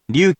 We have our computer friend, QUIZBO™, here to read each of the hiragana aloud to you.
In romaji, 「りゅ」 is transliterated as 「ryu」which sounds like 「lyew*」or 「lyuu*」